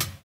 Closed Hats
HiHat (Question Mark).wav